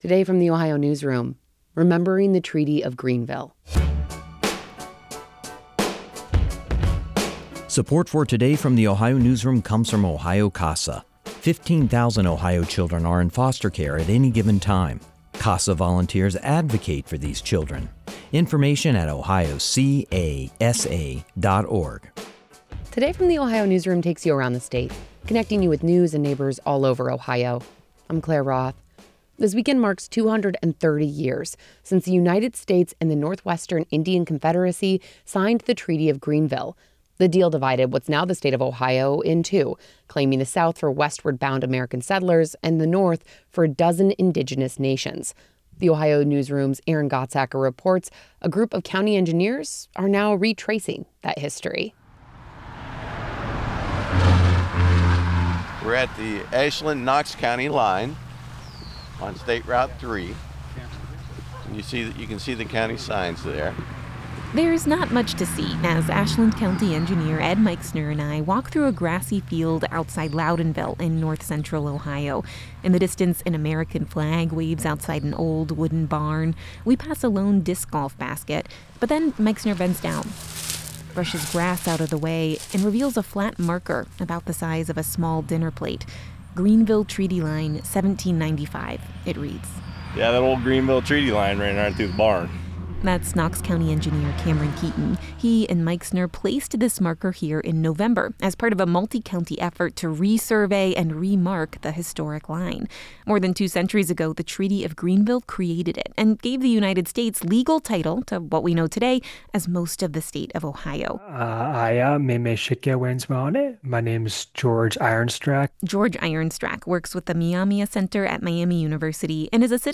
The grassy field beside a two-lane highway near Loudonville in north central Ohio is nothing special.
"We're at the Ashland-Knox County line on State Route 3. You can see the county signs there," Ashland County Engineer Ed Meixner pointed out.